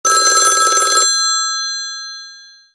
zvonec
zvonec.wav